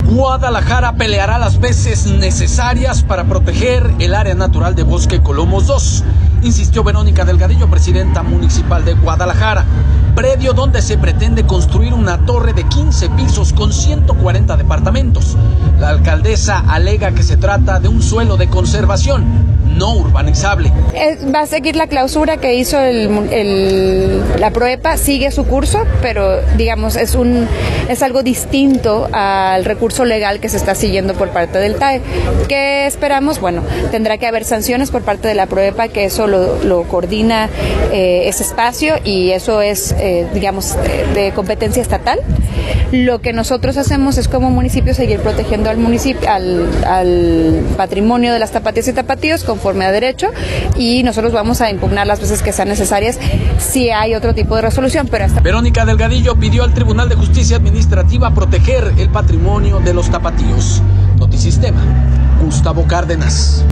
audio Guadalajara peleará las veces necesarias para proteger el área natural de Bosque Colomos 2, insistió Verónica Delgadillo, presidenta municipal de Guadalajara. Predio donde se pretende construir una torre de 15 pisos con 140 departamentos, la alcaldesa alega que se trata de un suelo de conservación, no urbanizable.